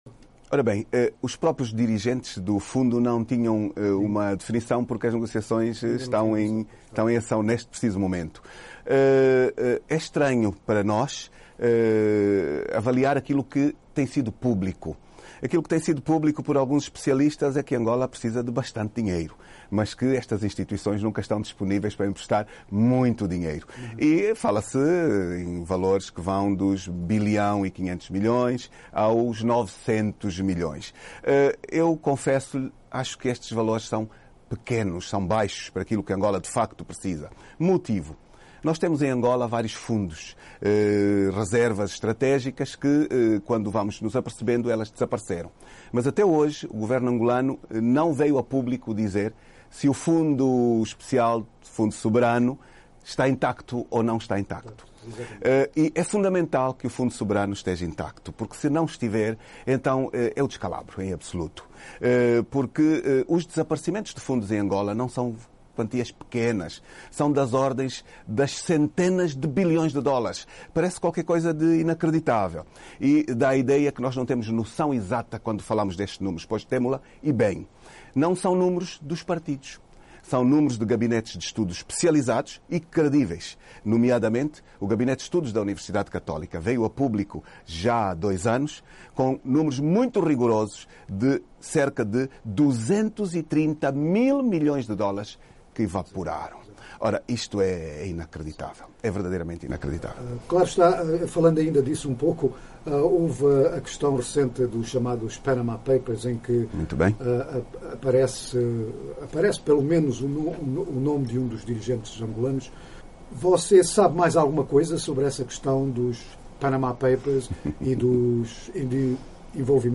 Em entrevista à VOA nesta quarta-feira, 13, em Washington, o parlamentar disse que as eleições de 2017 vão decorrer num ambiente totalmente diferente das últimas porque o maior sector da população é a juventude e hoje tem à sua disposição meios de acesso a informação que não existiam anteriormente como a internet e as redes sociais nos telefones celulares.
Adalberto Costa Junior entrevista - 3:30